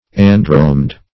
Andromede \An"dro*mede\, Andromed \An"dro*med\, n.] (Astron.)